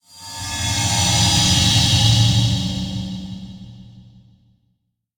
teleport3.ogg